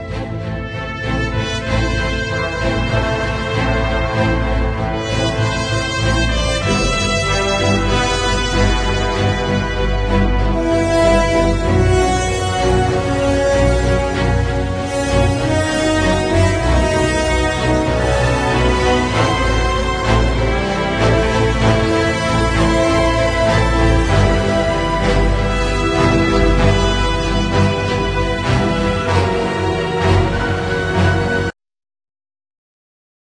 86k Uncredited action cue to the film